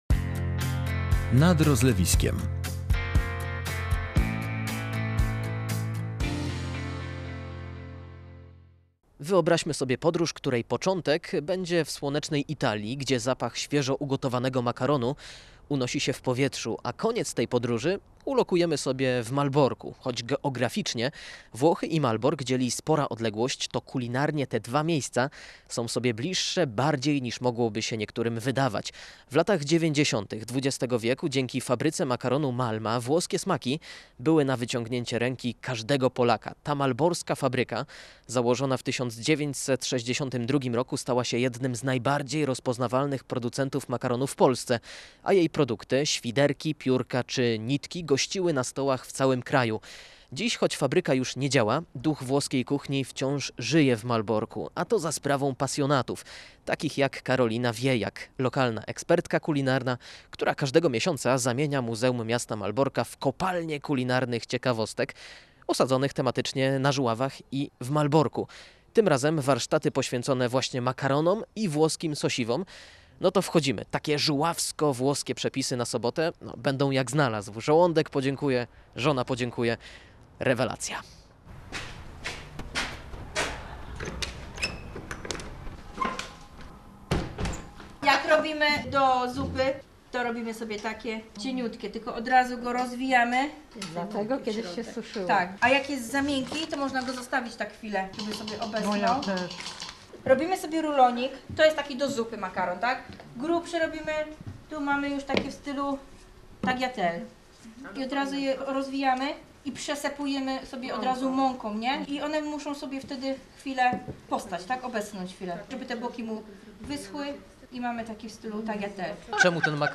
Makaronowe warsztaty w Muzeum Miasta Malborka (fot.